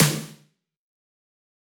drum-hitnormal.wav